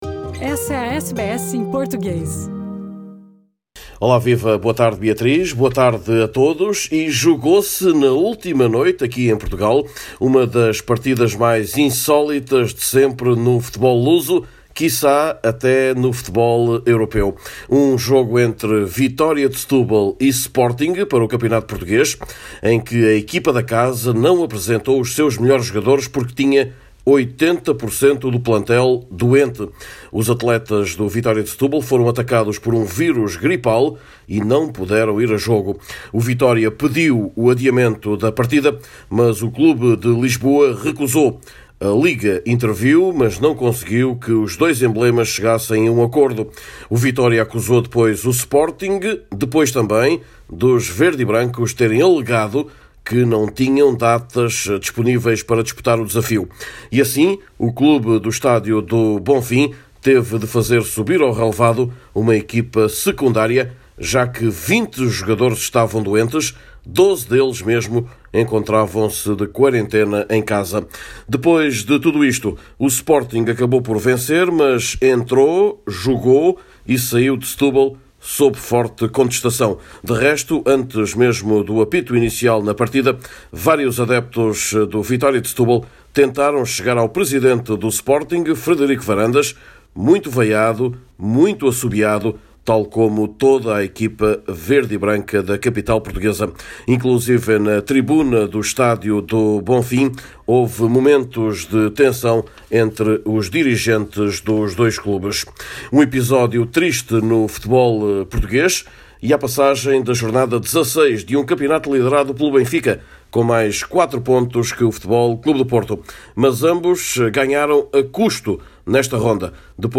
Neste boletim semanal do desporto em Portugal, também as contas da liga portuguesa, o mercado de transferências ou o feito do andebol luso no Europeu que decorre na Noruega.